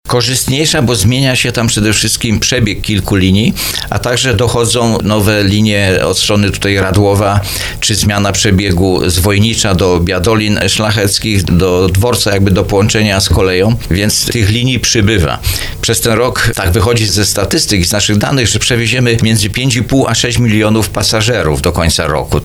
Tymczasem jak mówił na antenie RDN Małopolska radny sejmiku województwa małopolskiego Józef Gawron, poszerzenie działalności w zachodniej części powiatu tarnowskiego wyjdzie na korzyść wielu mieszkańcom.